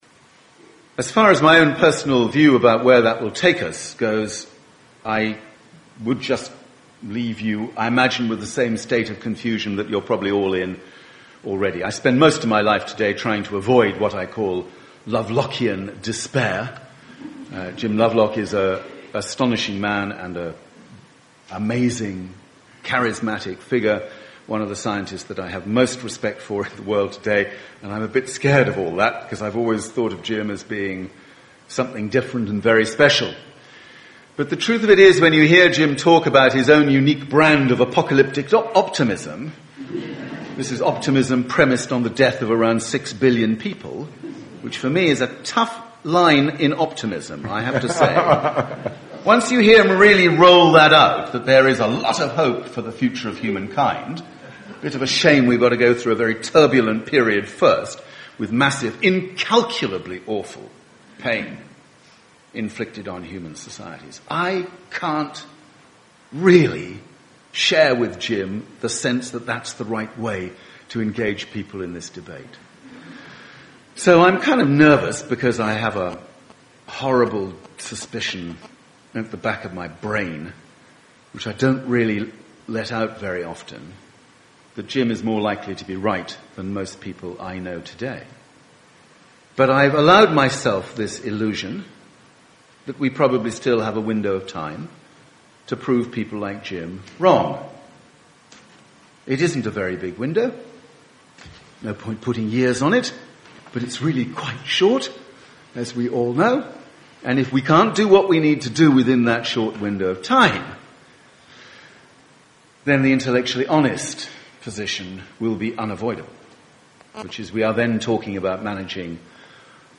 OPT - Presentation Extract: Porritt - mp3 1.2M